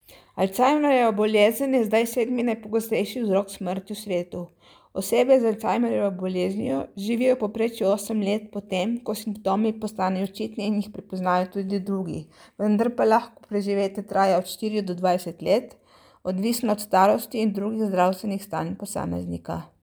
Tonske izjave: